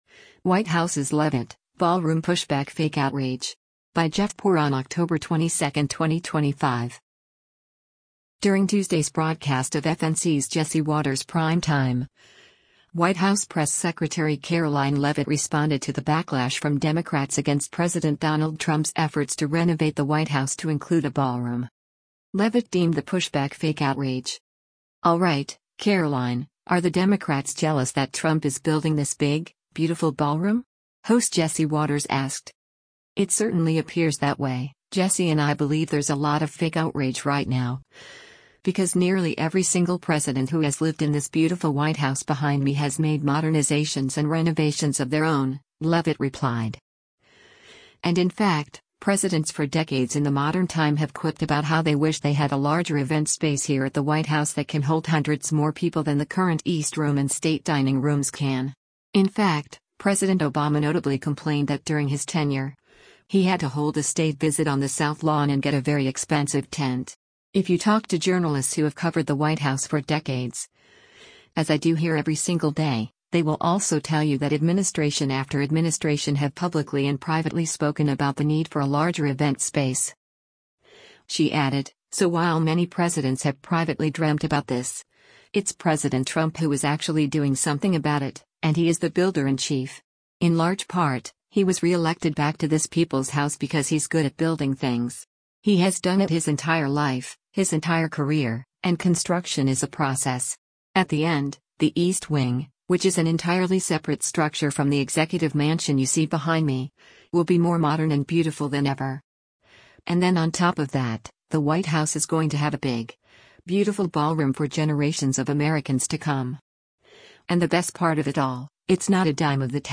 During Tuesday’s broadcast of FNC’s “Jesse Watters Primetime,” White House press secretary Karoline Leavitt responded to the backlash from Democrats against President Donald Trump’s efforts to renovate the White House to include a ballroom.